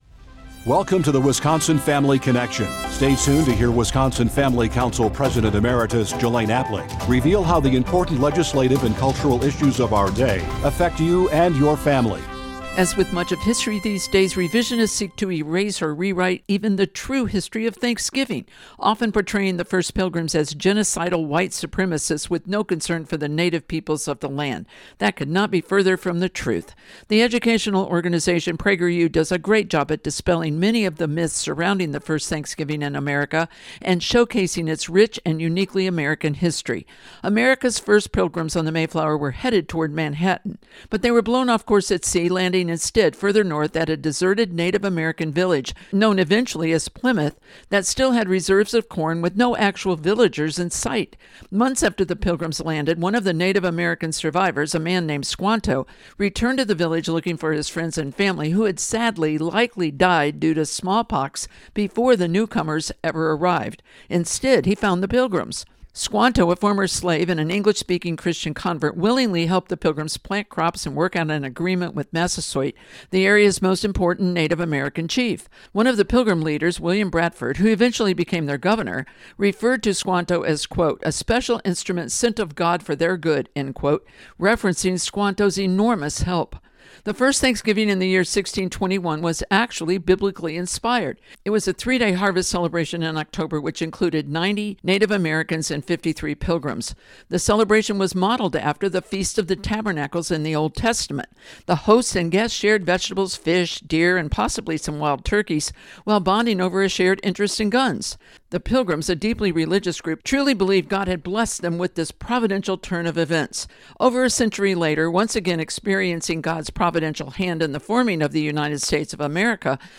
2024 | Week of November 25 | Radio Transcript #1594